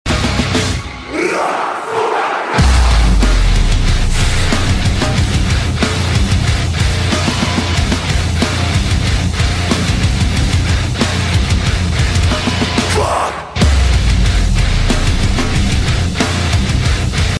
Wind in the face, freedom sound effects free download
freedom Mp3 Sound Effect Wind in the face, freedom in the soul—just the Scout Bobber and the breeze.